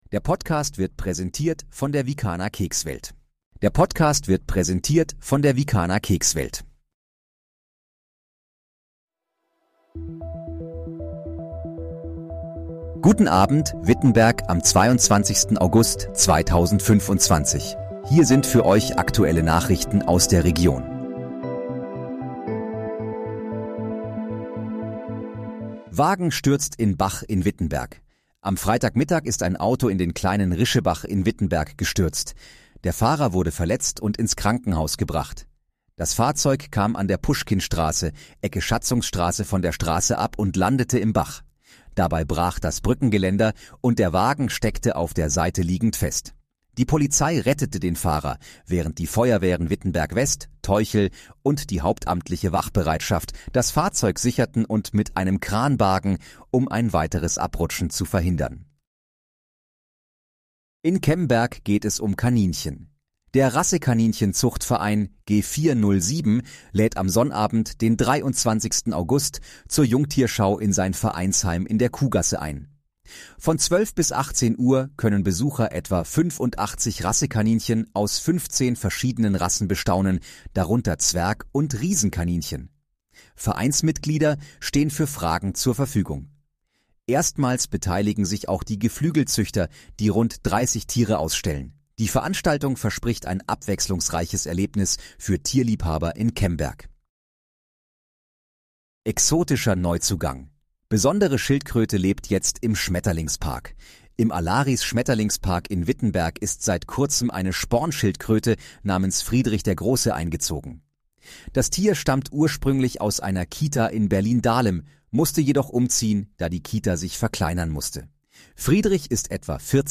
Guten Abend, Wittenberg: Aktuelle Nachrichten vom 22.08.2025, erstellt mit KI-Unterstützung